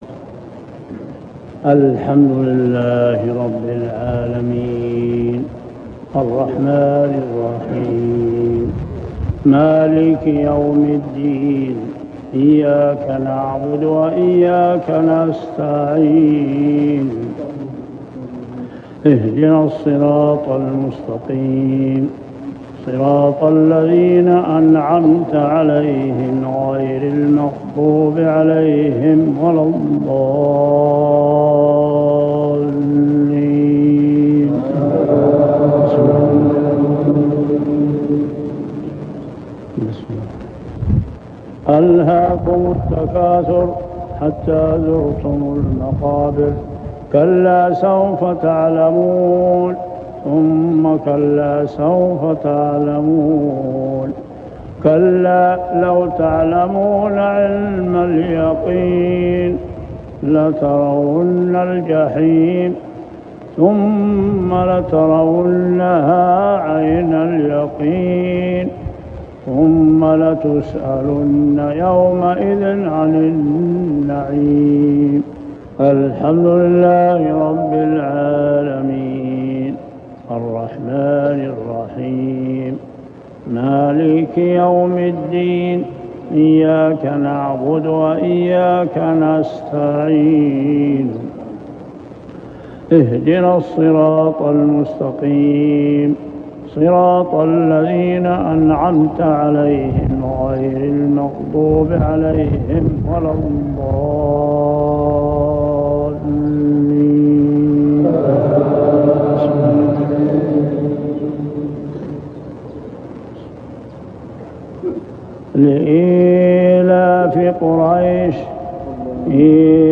صلاة المغرب عام 1406هـ سورتي التكاثر و قريش كاملة | Maghrib prayer Surah at-Takathur and al-Quraish > 1406 🕋 > الفروض - تلاوات الحرمين